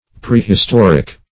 Prehistoric \Pre`his*tor"ic\, a.